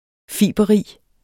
Udtale [ -ˌʁiˀ ]